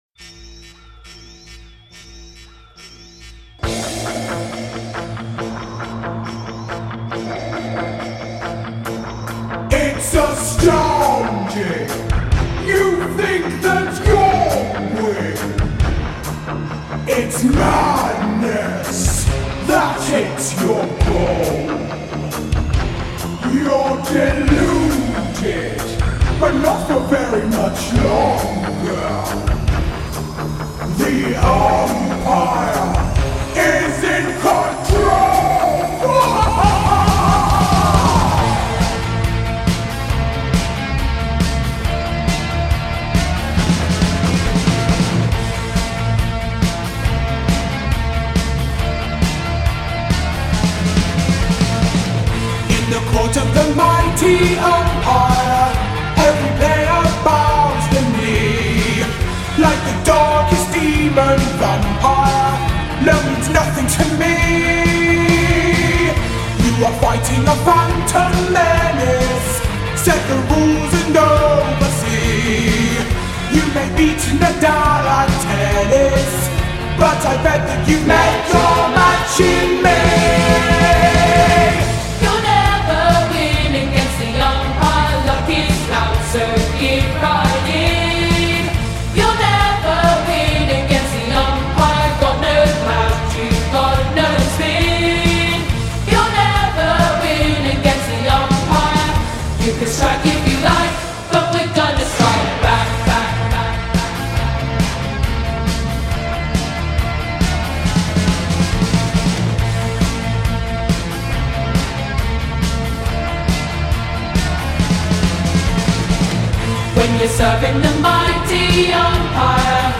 The Umpire Strikes Back_Bâ__minor__bpm_138.mp3